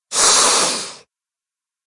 PixelPerfectionCE/assets/minecraft/sounds/mob/cat/hiss2.ogg at mc116
hiss2.ogg